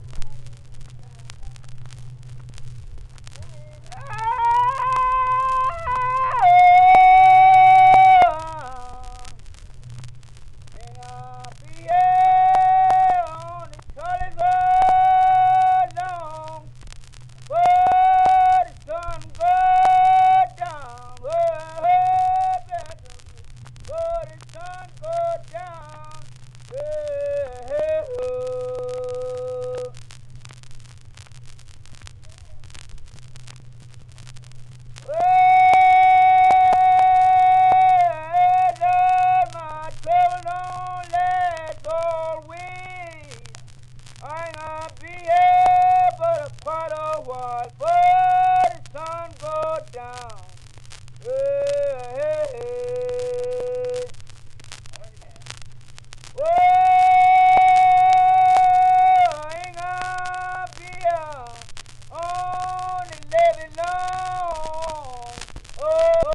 Levee Holler